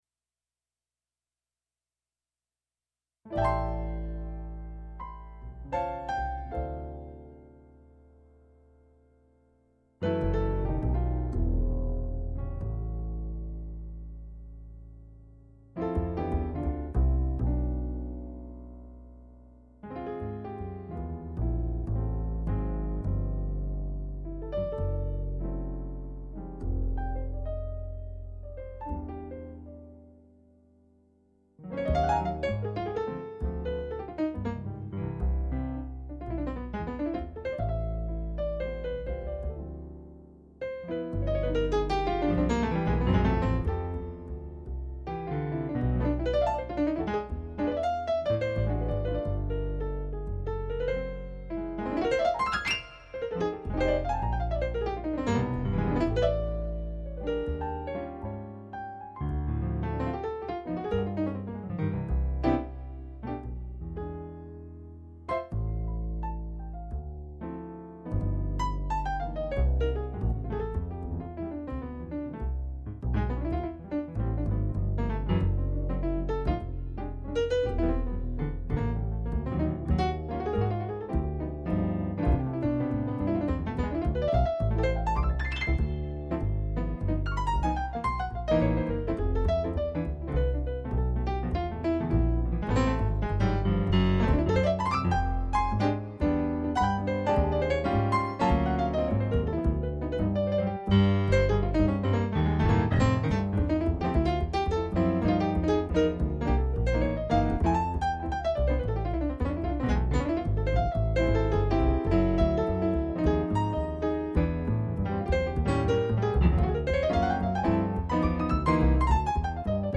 Piano Duo